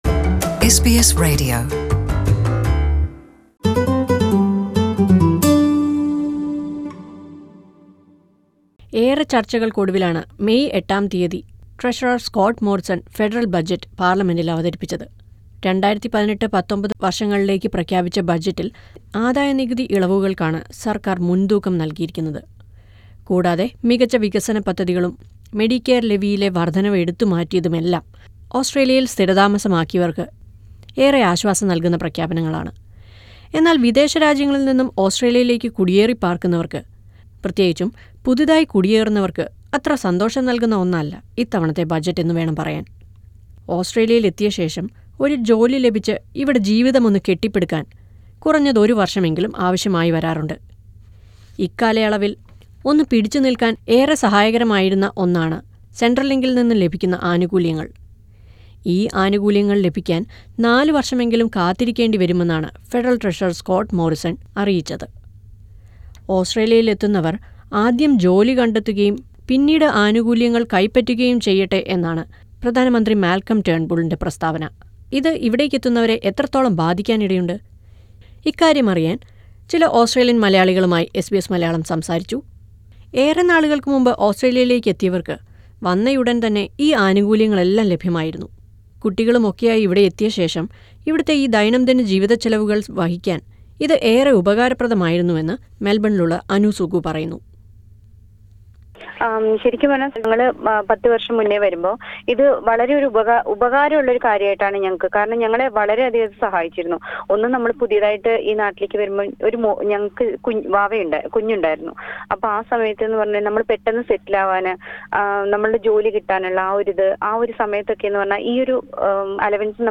The new migrants will have to wait for four years to receive the centrelink benefits according to budget 2018. SBS Malayalam spoke to a few new migrants on their opinions on how this change will affect the migrants coming to Australia. Listen to the report from the above player.